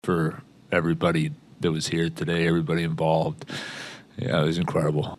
Coach Dan Muse was awestruck by Crosby’s accomplishment.